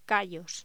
Locución: Callos